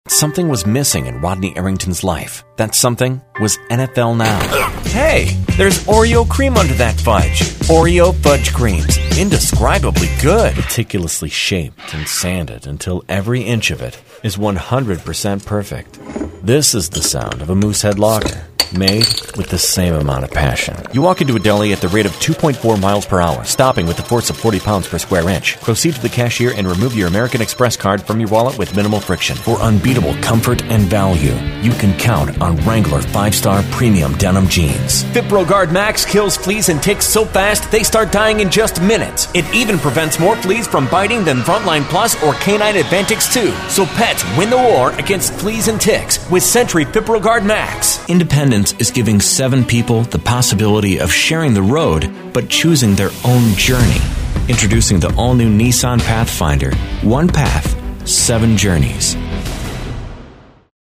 广告【大气沉稳】